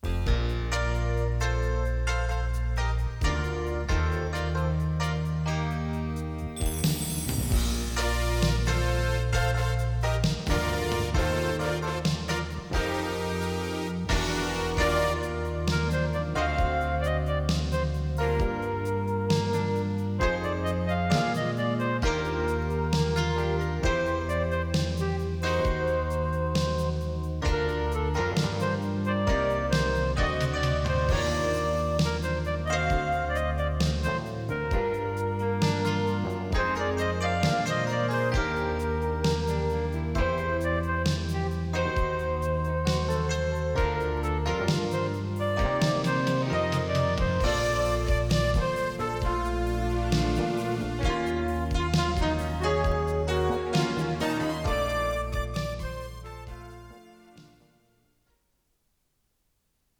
使用するデッキは、SONYのTC-K555ESRです。
★バイアスは適正、感度が低い時、ドルービーNR-BタイプをONの再生音★
SONY-C-90-HFB-DOLBY-B.wav